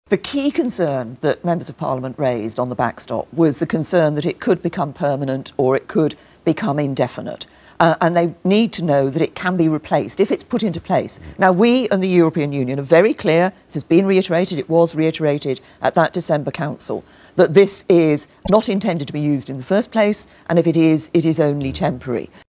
Speaking on the BBC this morning, Theresa May tried to allay the fears of MPs who are concerned it could remain in place indefinitely.
Theresa May says both her and the EU’s position on the backstop is clear: